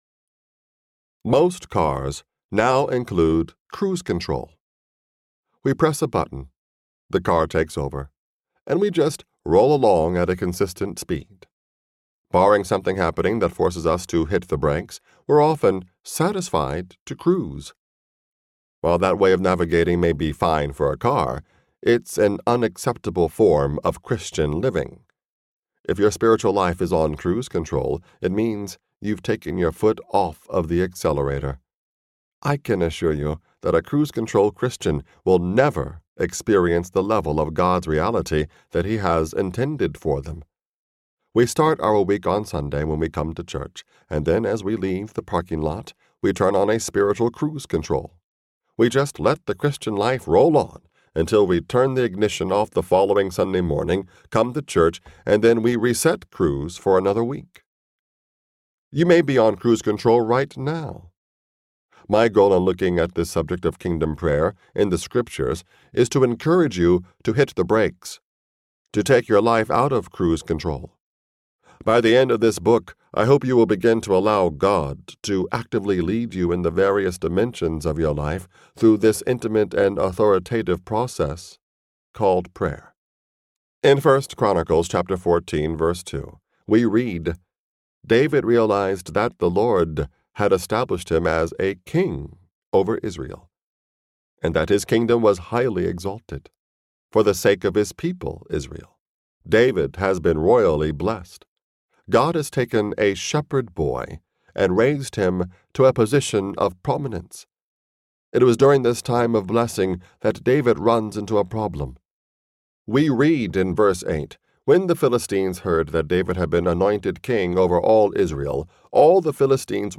Kingdom Prayer Audiobook
Narrator